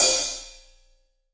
AMB_OPHH1.WAV